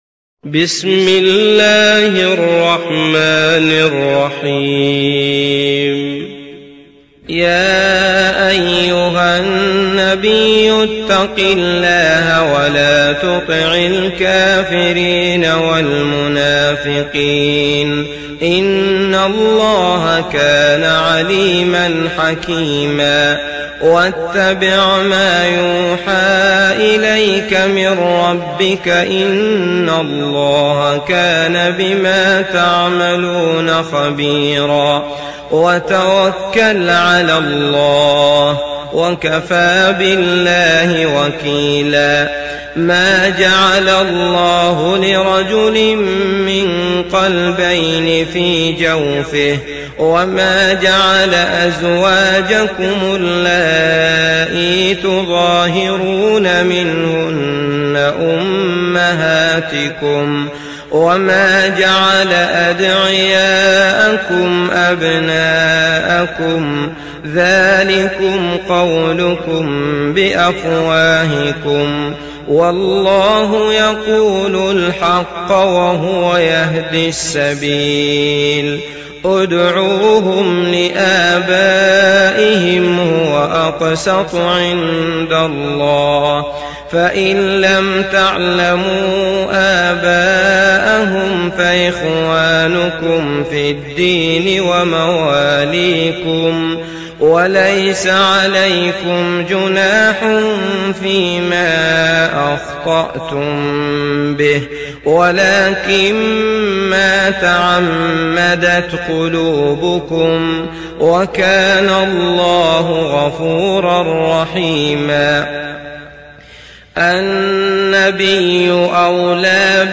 تحميل سورة الأحزاب mp3 بصوت عبد الله المطرود برواية حفص عن عاصم, تحميل استماع القرآن الكريم على الجوال mp3 كاملا بروابط مباشرة وسريعة